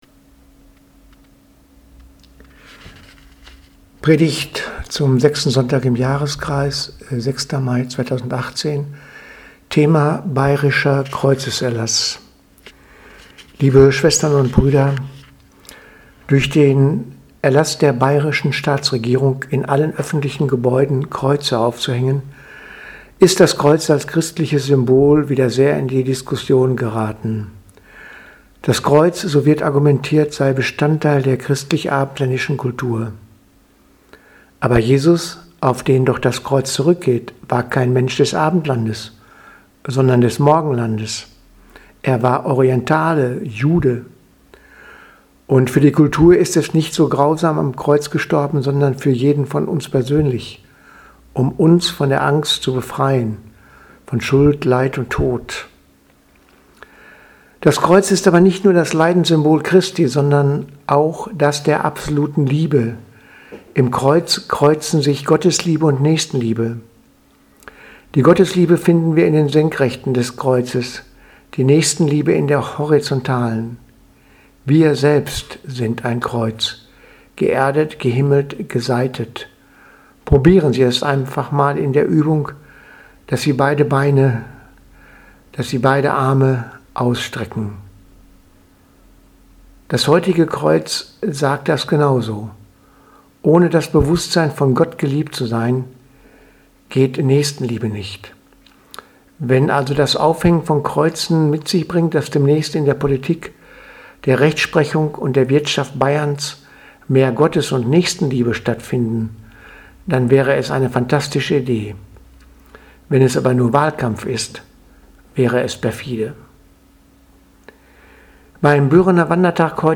Predigt vom 06.05.2018 Ostersonntag